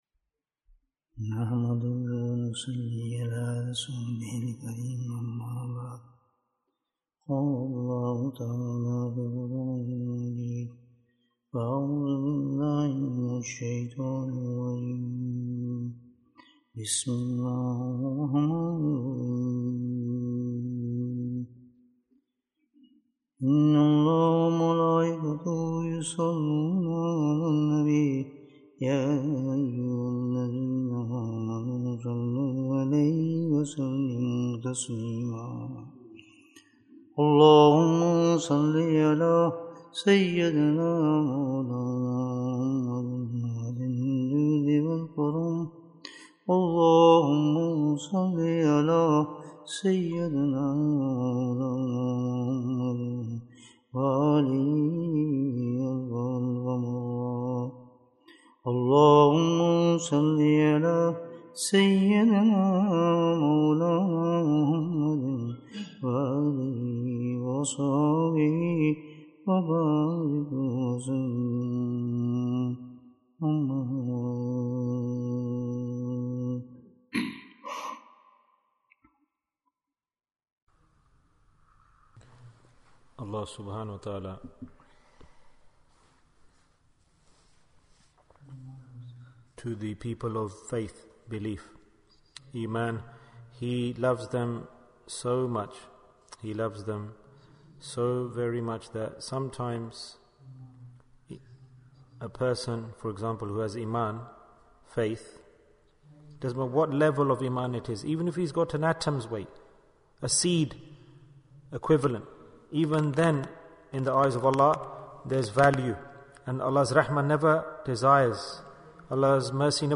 How Should We Celebrate Jumu'ah? Bayan, 48 minutes19th January, 2023